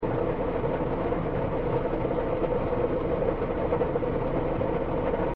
drill.mp3